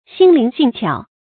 心靈性巧 注音： ㄒㄧㄣ ㄌㄧㄥˊ ㄒㄧㄥˋ ㄑㄧㄠˇ 讀音讀法： 意思解釋： 心思靈巧。